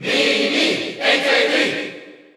Category: Crowd cheers (SSBU)
Mii_Cheer_Dutch_SSBU.ogg